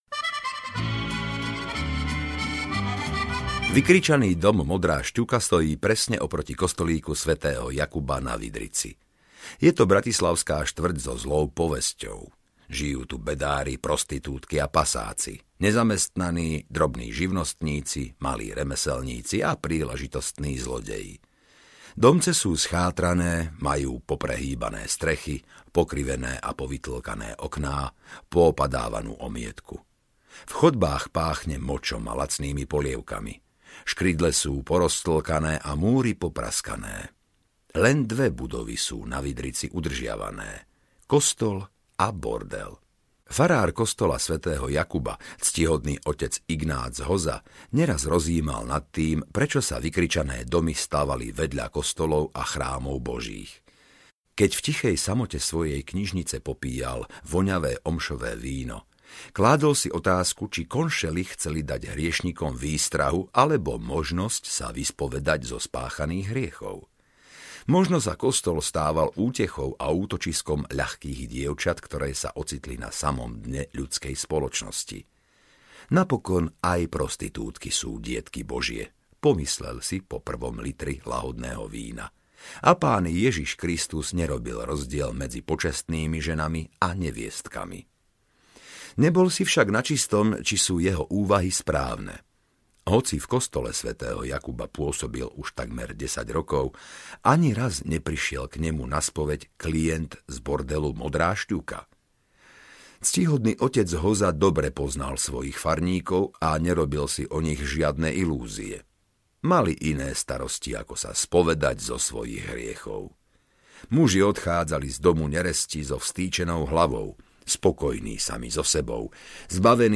Diabolská maska audiokniha
Ukázka z knihy